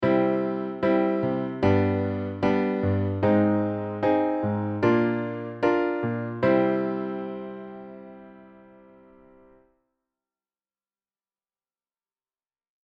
コードネームはⅥ♭、通名「傾国の美女」さんです。
Ｂのお仕事の方だと力強さも感じるよっ！